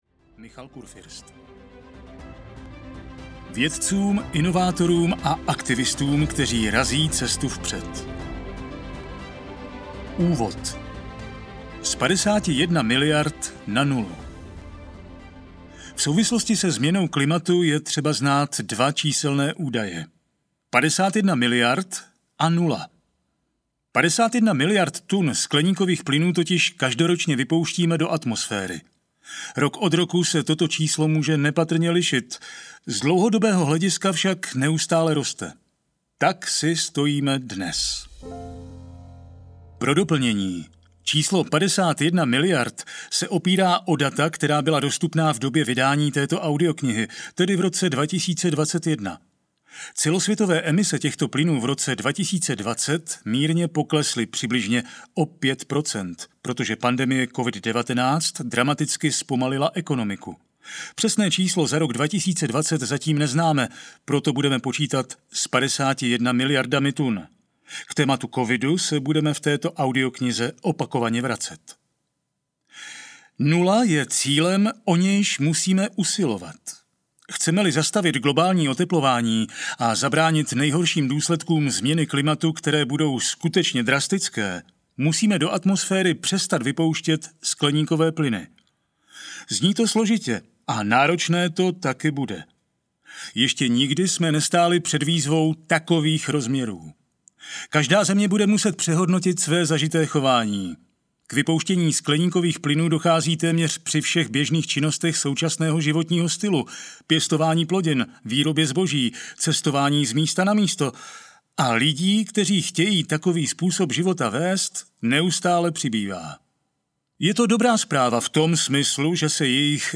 Jak zabránit klimatické katastrofě audiokniha
Ukázka z knihy